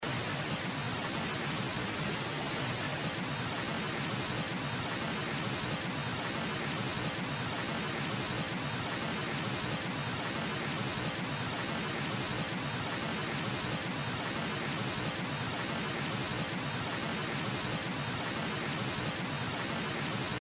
Звуки шума в ушах
Здесь можно послушать и скачать примеры гула, звона, шипения и других фантомных шумов, которые некоторые люди воспринимают субъективно.